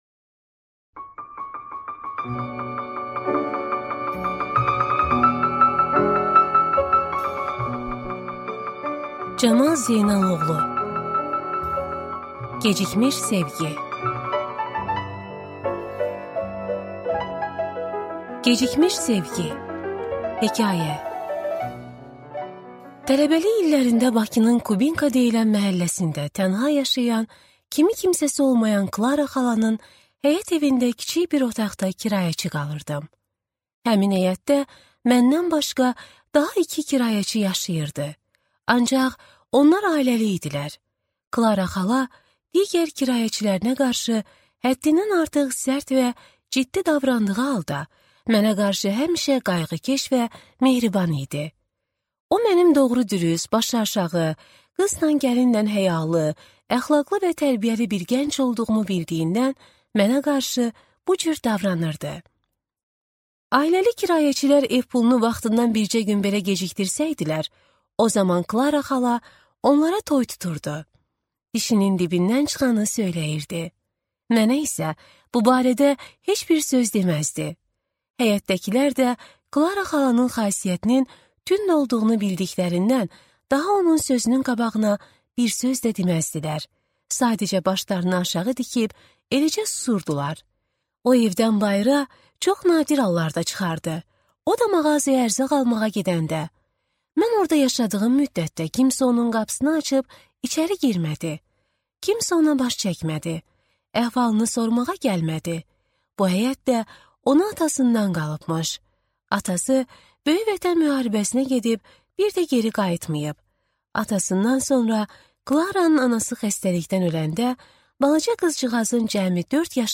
Аудиокнига Gecikmiş sevgi | Библиотека аудиокниг
Прослушать и бесплатно скачать фрагмент аудиокниги